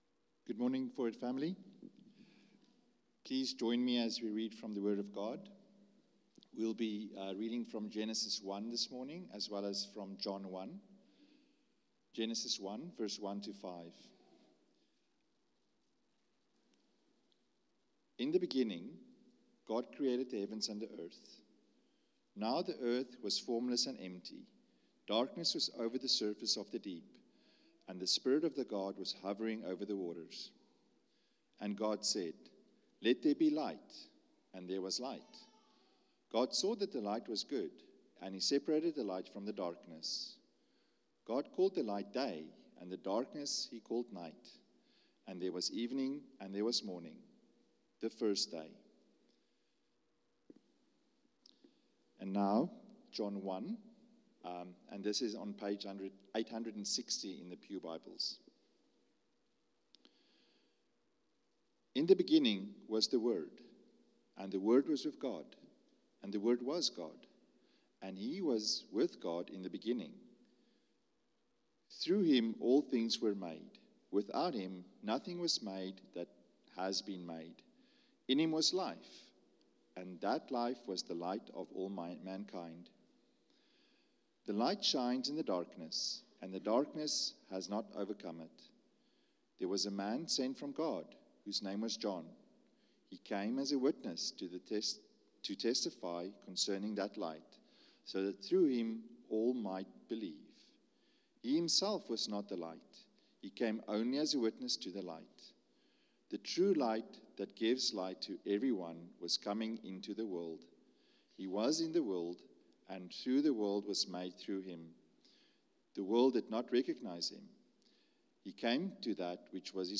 Sermons - Forward Baptist Church, Toronto